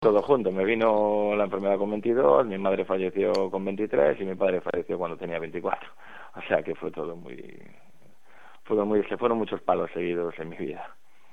con voz entrecortada formato MP3 audio(0,22 MB).